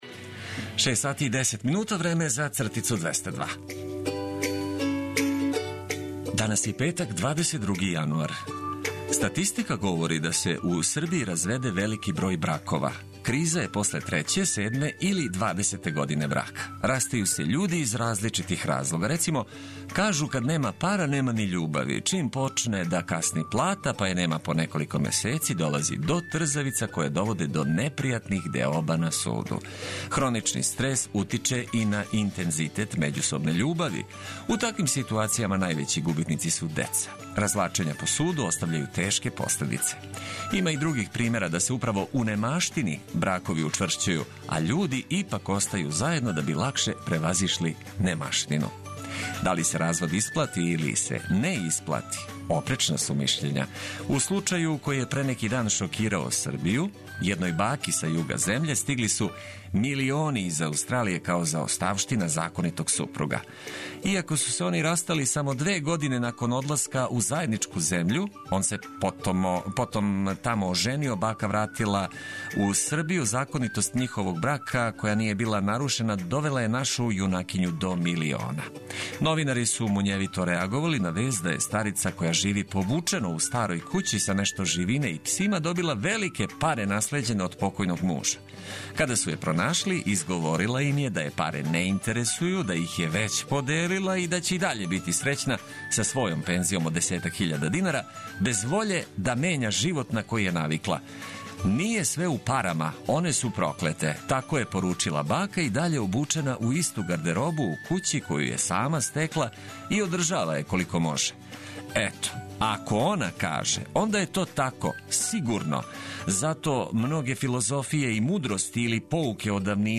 И овог јутра добра музика и корисне информације биће обележје јутарњег сусретања са вама.